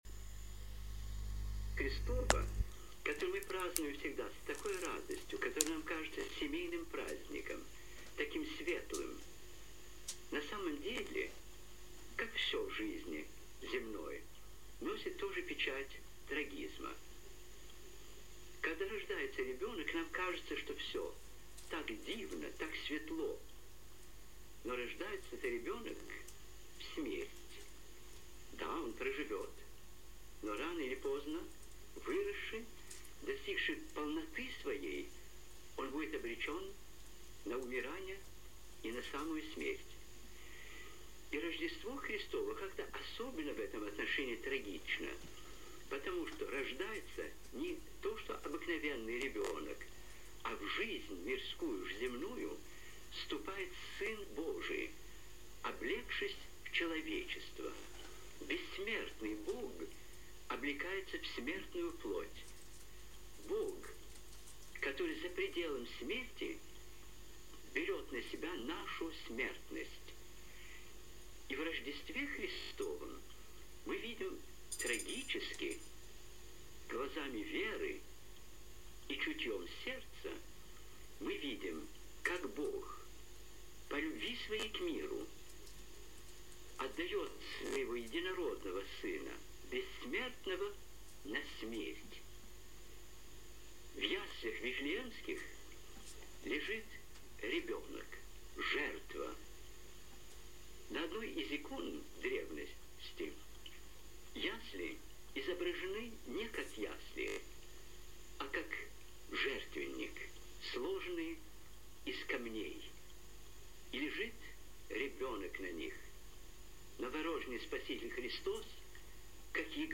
Проповедь митрополита Сурожского Антония на Рождество Христово.